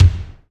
Index of /90_sSampleCDs/Sound & Vision - Gigapack I CD 1 (Roland)/KIT_REAL m 9-12/KIT_Real-Kit m11
BD BD2V.wav